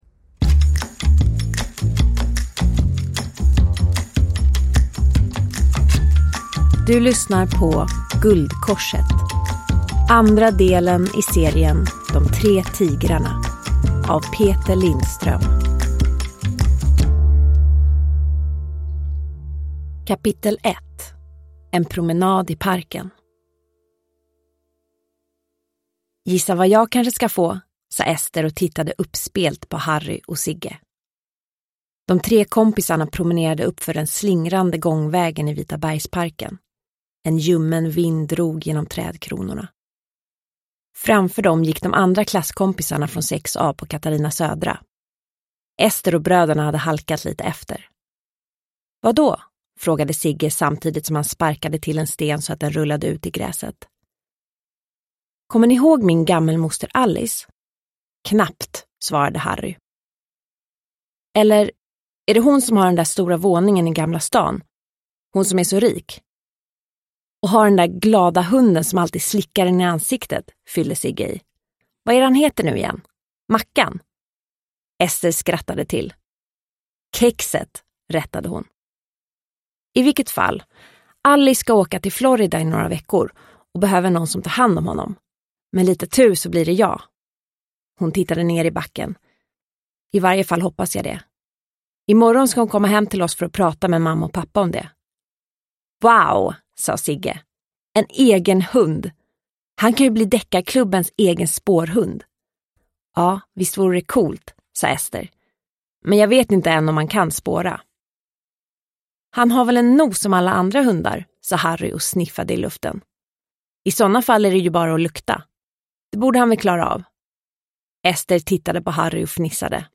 Guldkorset – Ljudbok – Laddas ner